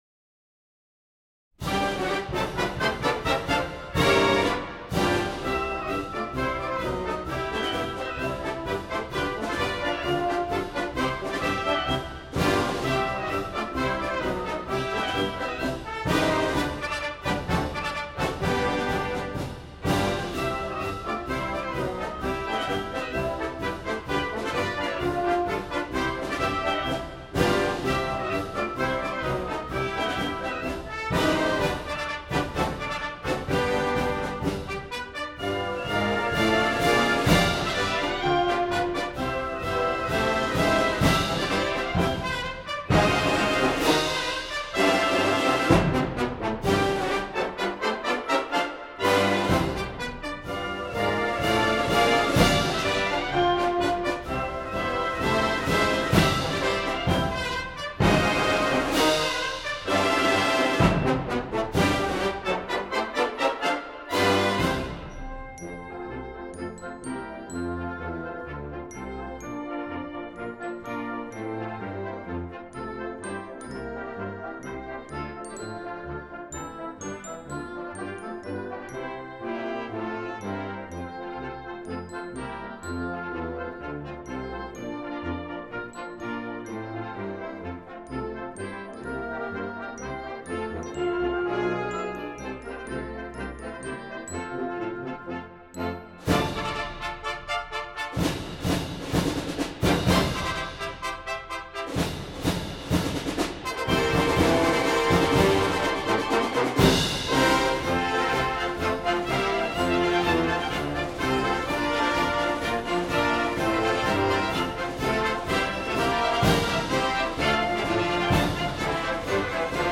2b. Concert Band
concert band